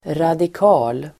Uttal: [radik'a:l]